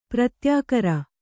♪ pratyākara